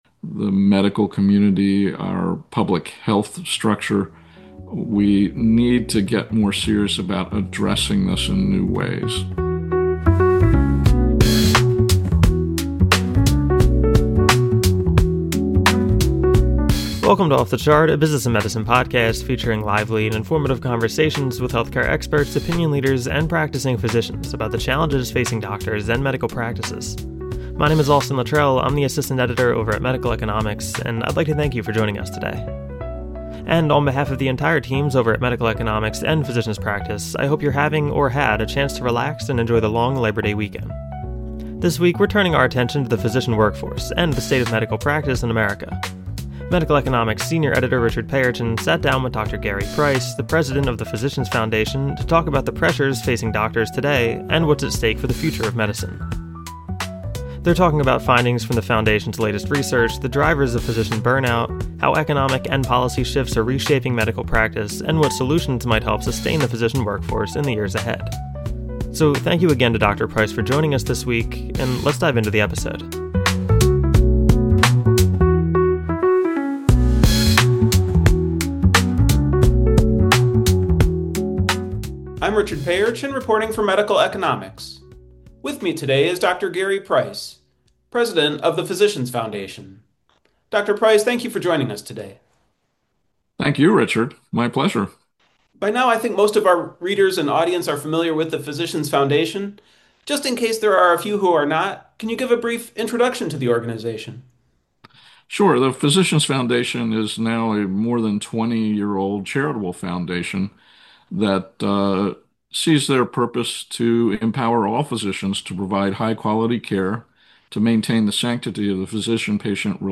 Jazz Lounge